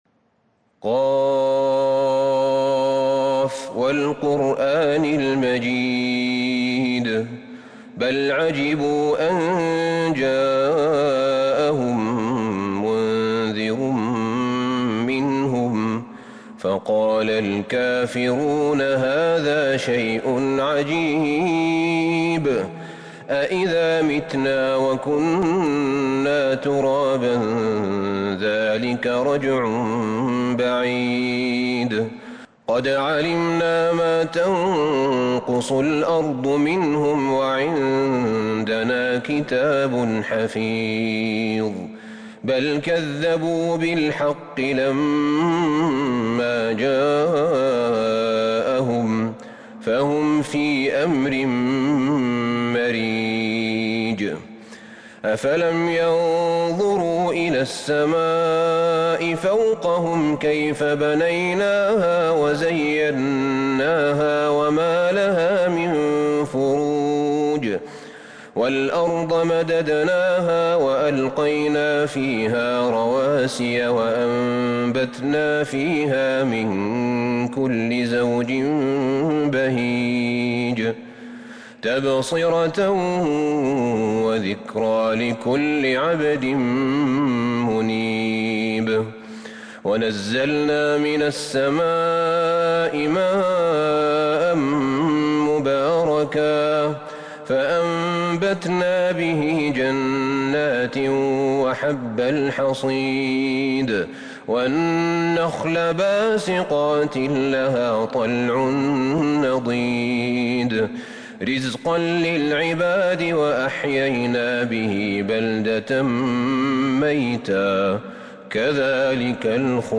سورة ق Surat Qaaf من تراويح المسجد النبوي 1442هـ > مصحف تراويح الحرم النبوي عام 1442هـ > المصحف - تلاوات الحرمين